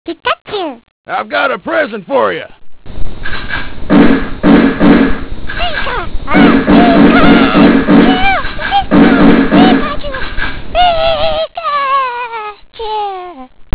pikadielowquality.wav